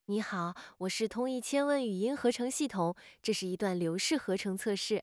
IntuitionX_agent / test / tts / output / stream_test.wav